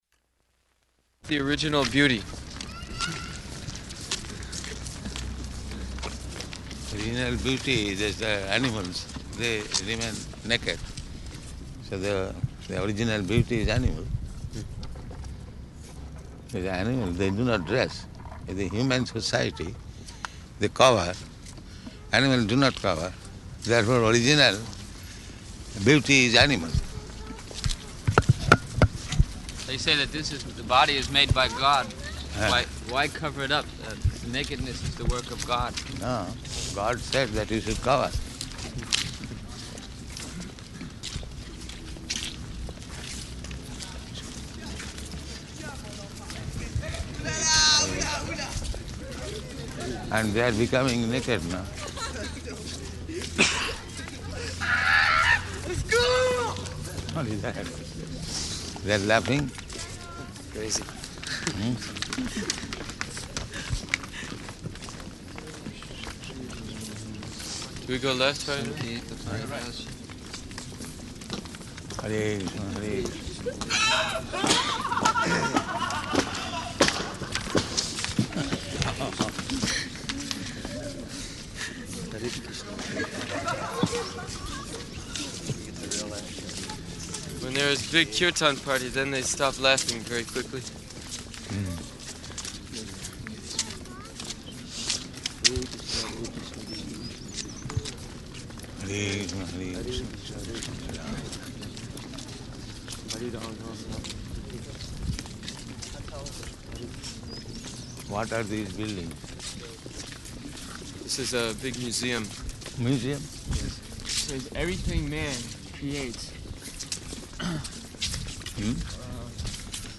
-- Type: Walk Dated: June 12th 1974 Location: Paris Audio file
[some French youths in background making mocking sounds, yelling, etc.]
[yelling increases] What is that?